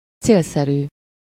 Ääntäminen
US : IPA : [ɪk.ˈspi.di.ənt]